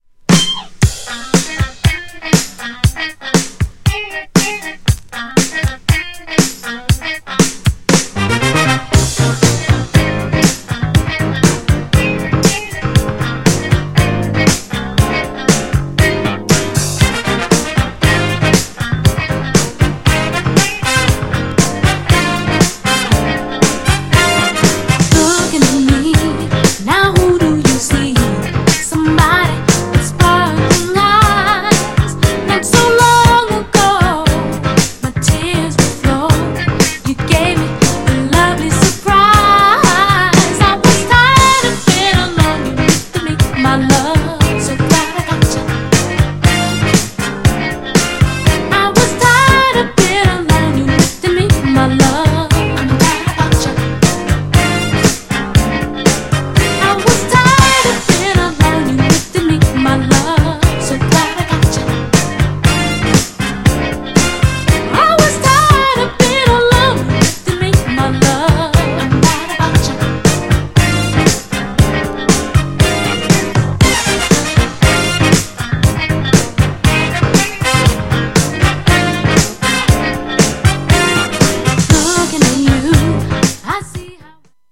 GENRE Dance Classic
BPM 91〜95BPM